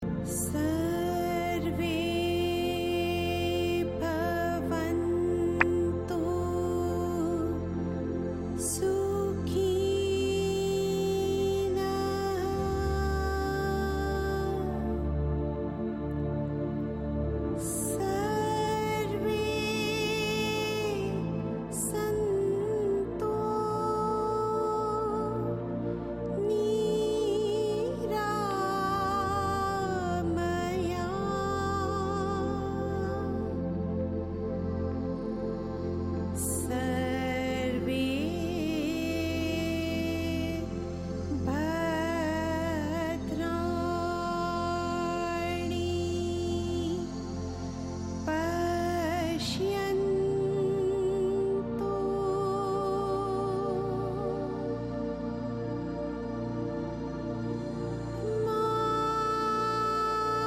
Deep Meditative journey
chants and mantras